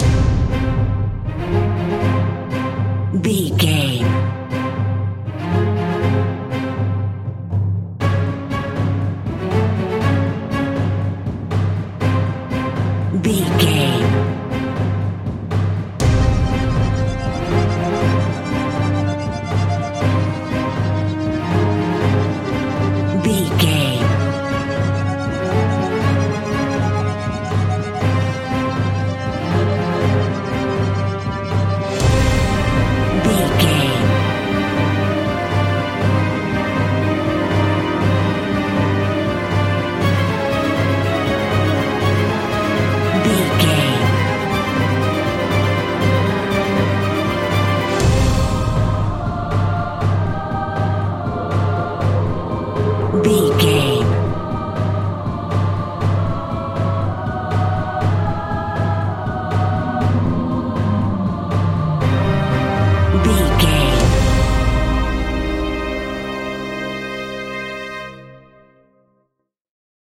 Dramatic Epic Action Film Cue.
In-crescendo
Aeolian/Minor
ominous
suspense
haunting
eerie
strings
synth
pads